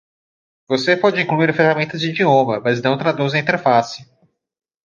Pronunciado como (IPA)
/i.d͡ʒiˈõ.mɐ/